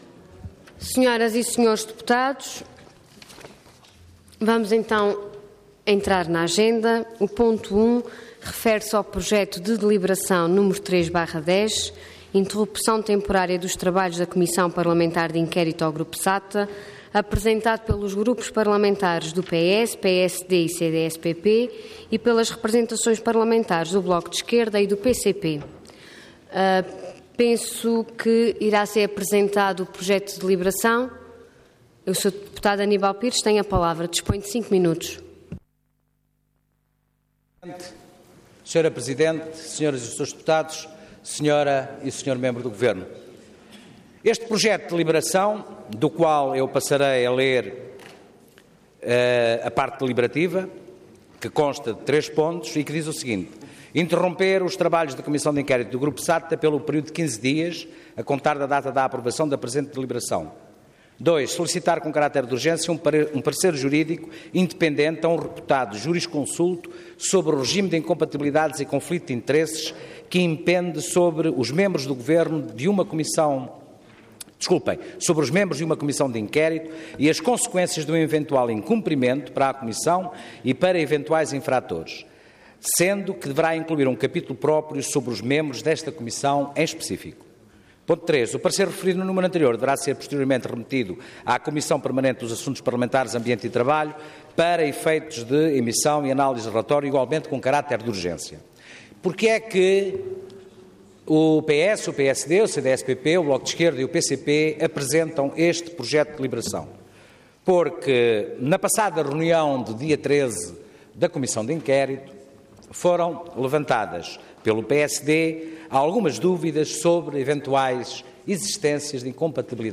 Intervenção
Orador Aníbal Pires Cargo Deputado